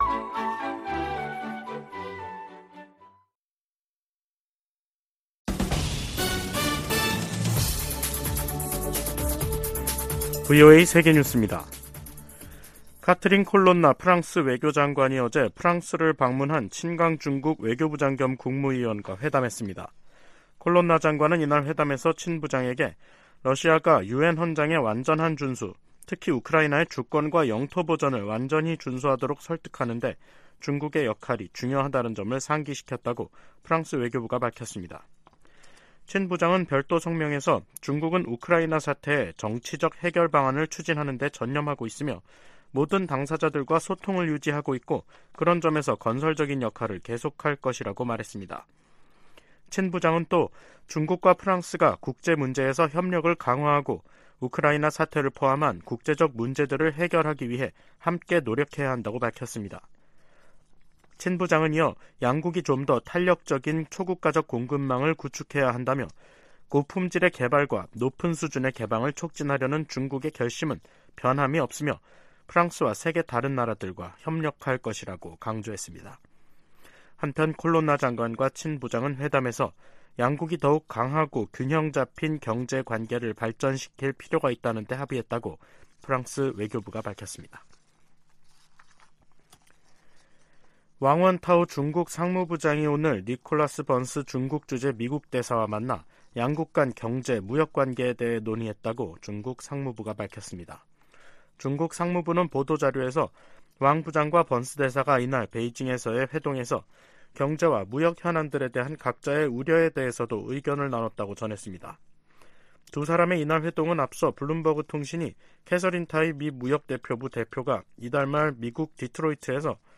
VOA 한국어 간판 뉴스 프로그램 '뉴스 투데이', 2023년 5월 11일 2부 방송입니다. 북한이 사이버 활동으로 미사일 자금 절반을 충당하고 있다고 백악관 고위 관리가 말했습니다. 미한 동맹이 안보 위주에서 국제 도전 과제에 함께 대응하는 관계로 발전했다고 미 국무부가 평가했습니다. 미 국방부가 미한일 3국의 북한 미사일 정보 실시간 공유를 위해 두 나라와 협력하고 있다고 확인했습니다.